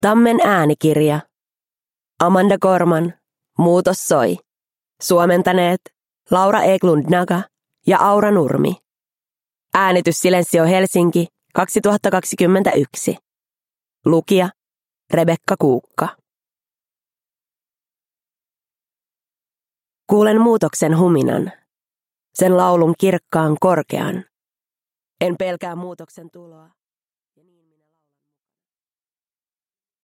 Muutos soi – Ljudbok – Laddas ner